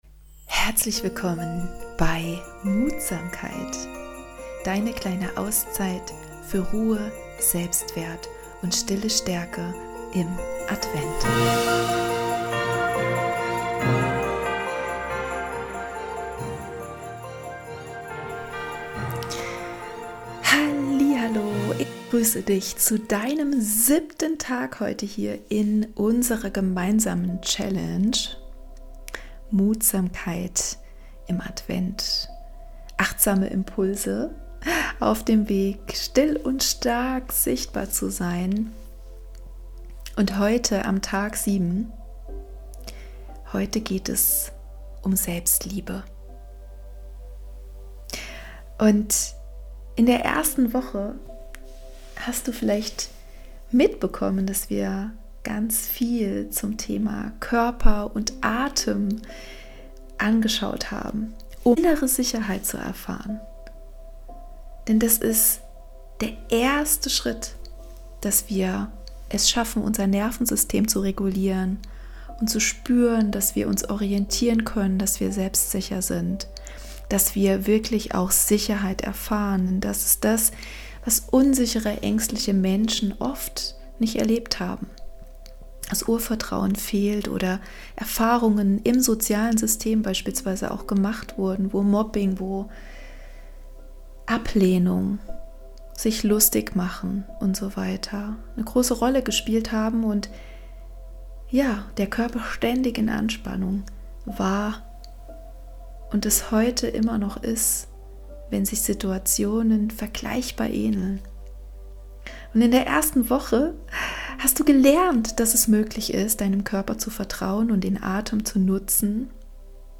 Mit einer ruhigen Achtsamkeitssequenz schenkst du deinem Körper